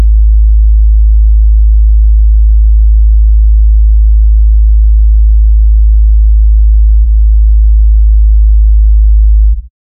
Deep sub-bass hum, smooth, constant, minimal resonance
deep-sub-bass-hum-smooth--aopgkliz.wav